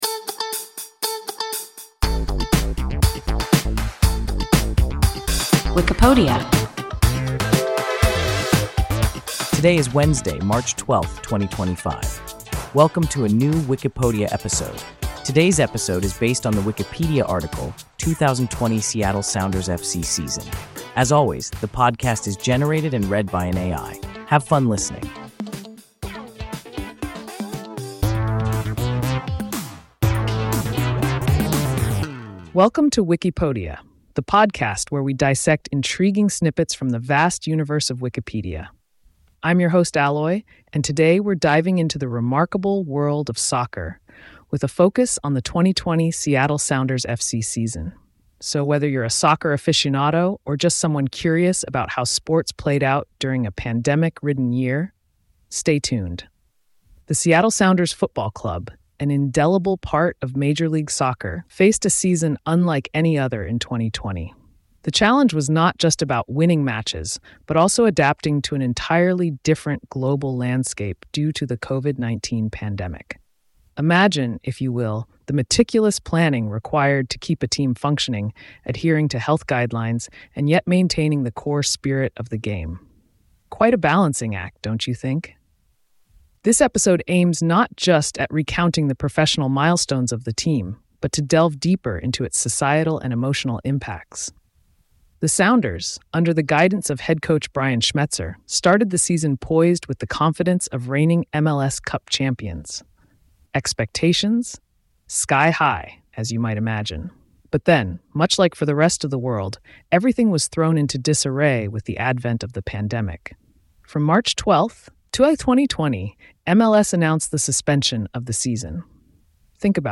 2020 Seattle Sounders FC season – WIKIPODIA – ein KI Podcast